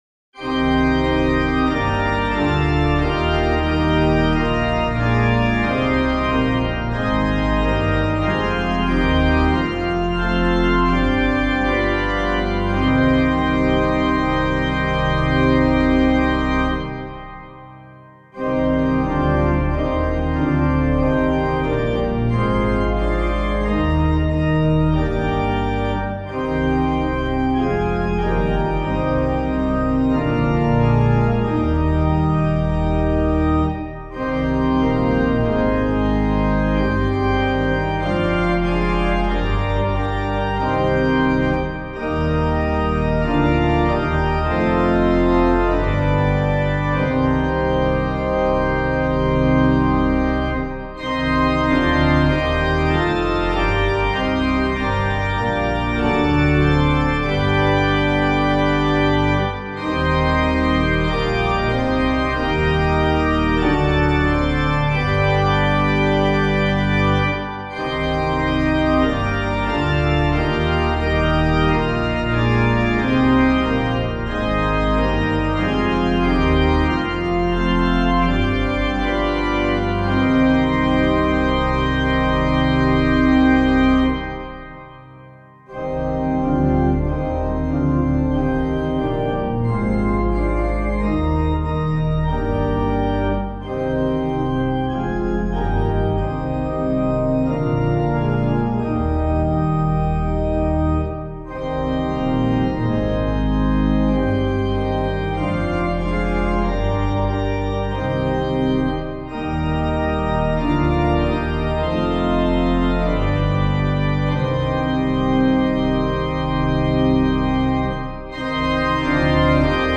Hymn suitable for Catholic liturgy.
Composer: traditional English folk tune. Comments: The arrangement here is generic; the far more popular arrangement by Ralph Vaughan Williams, is copyrighted (till 2029), but can be found in most hymnals.